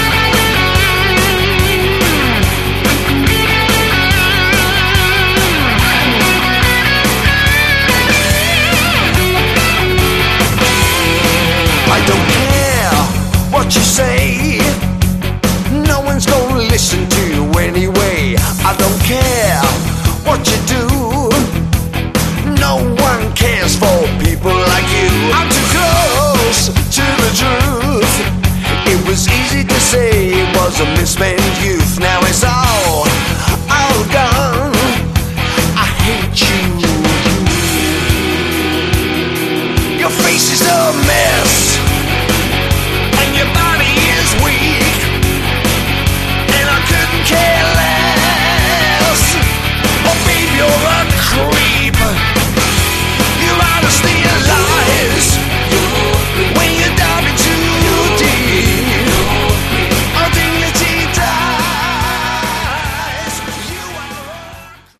Category: Melodic Hard Rock
Vocals
Guitars, Bass, Backing Vocals
Keyboards